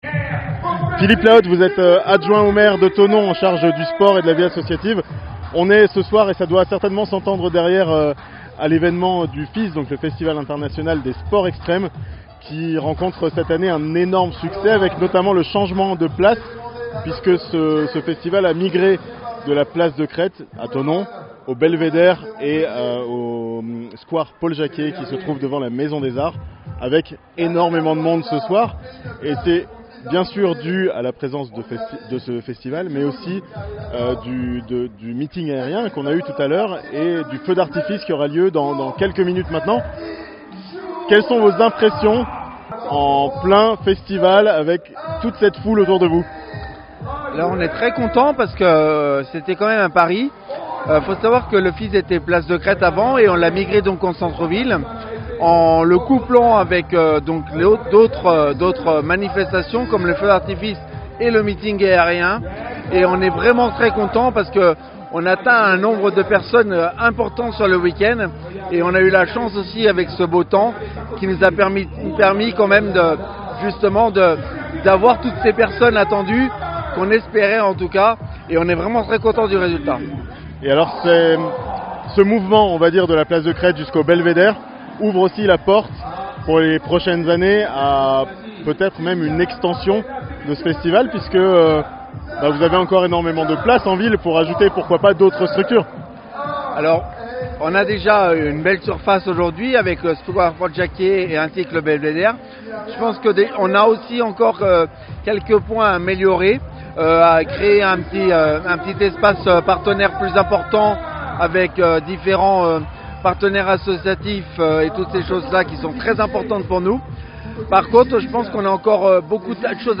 Enorme succès pour le FISE Experience, à Thonon (interview)
Philippe Lahotte, maire adjoint de Thonon en charge des sports et de la vie associative